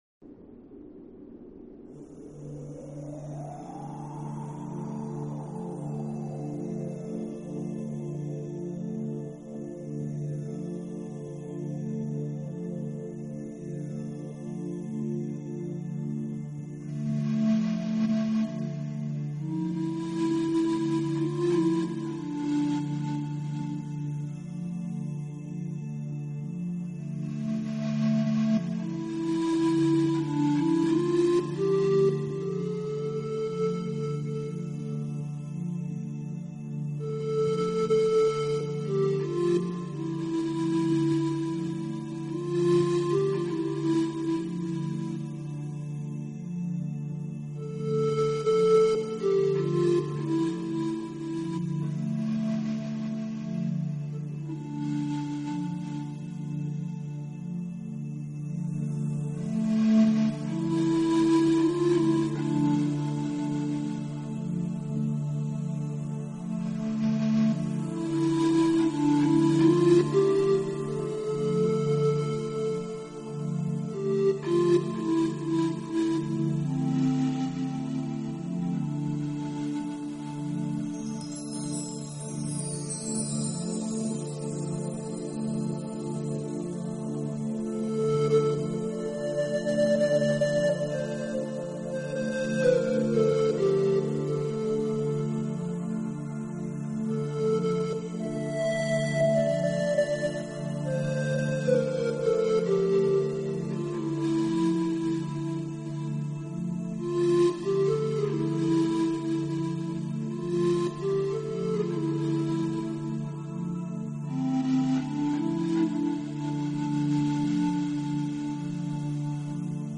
Genre..........: New Age